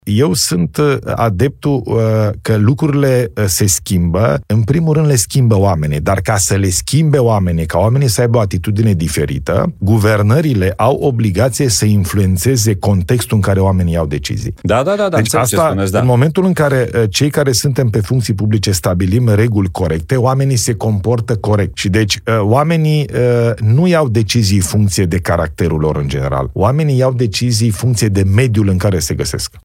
Într-un interviu pentru podcastul ApropoTv, Ilie Bolojan spune că țara a plătit anul acesta șase miliarde de lei pentru concediile medicale luate de români.